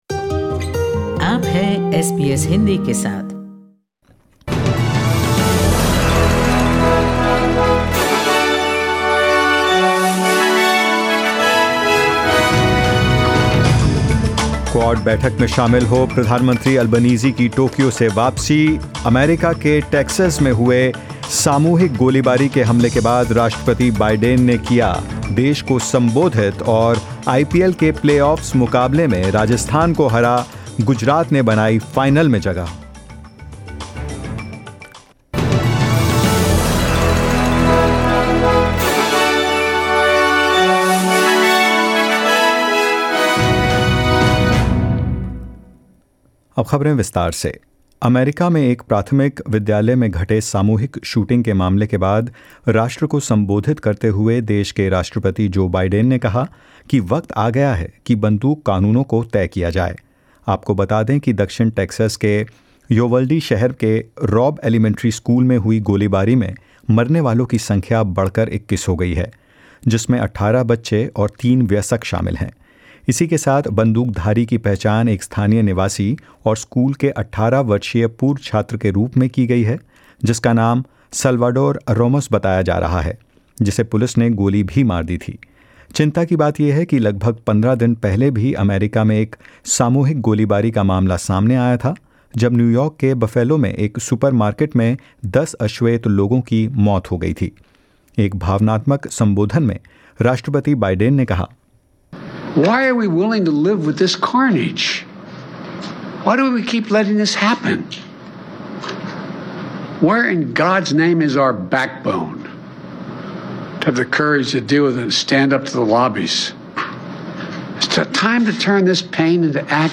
In this latest SBS Hindi bulletin: Another deadly school shooting in the US reveals frustration on gun control laws; Former women's champion Martina Navratilova bemused by talk that some players won't go to Wimbledon and more